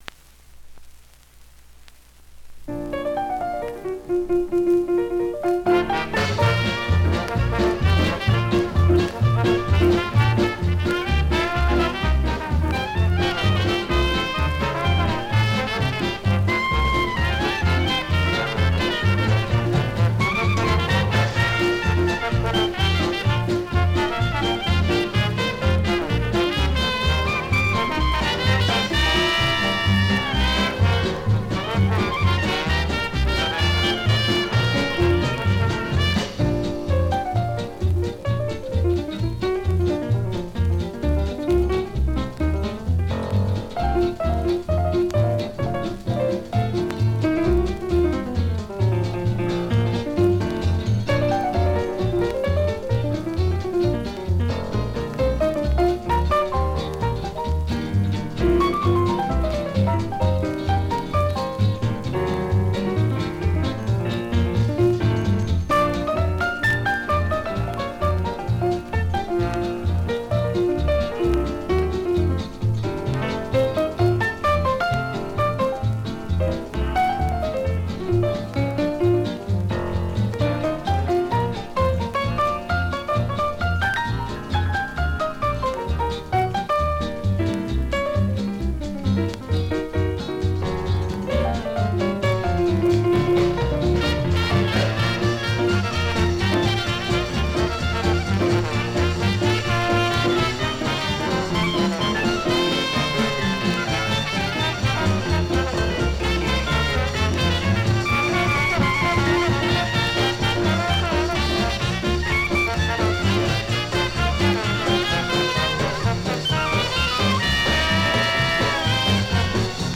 swinging, hot jazz band
I like the ride cymbal sound too.